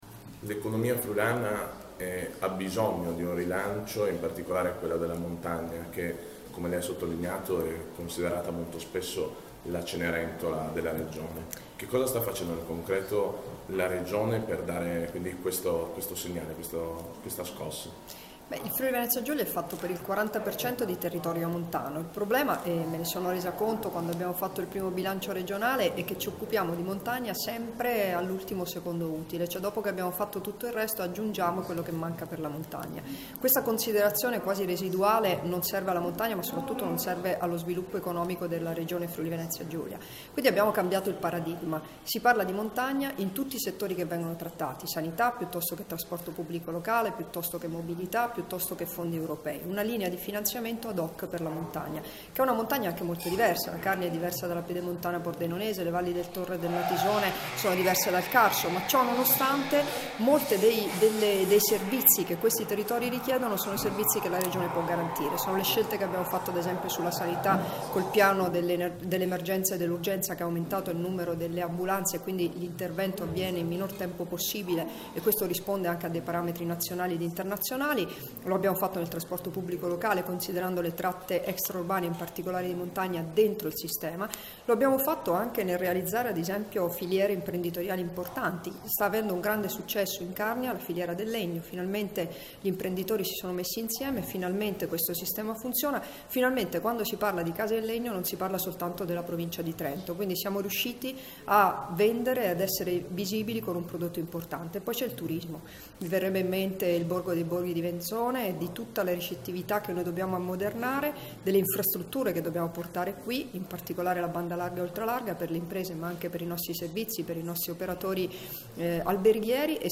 Dichiarazioni di Debora Serracchiani (Formato MP3) [4387KB]
al 3° Forum del Rotary club di Tolmezzo "Economia della montagna friulana: una sfida possibile", rilasciate ad Amaro il 29 aprile 2017